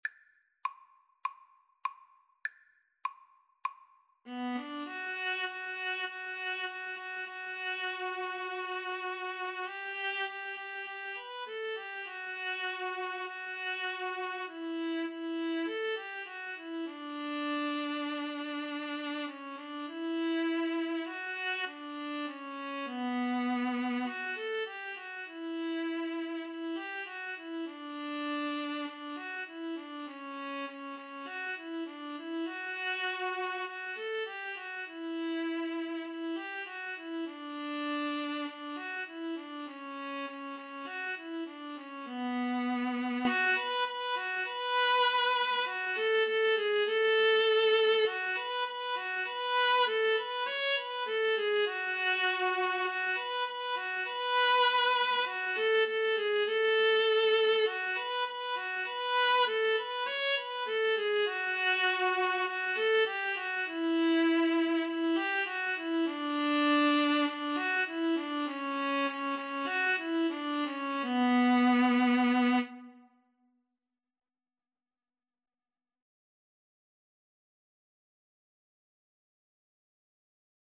Free Sheet music for Viola Duet
B minor (Sounding Pitch) (View more B minor Music for Viola Duet )
4/4 (View more 4/4 Music)
Moderato
Traditional (View more Traditional Viola Duet Music)
world (View more world Viola Duet Music)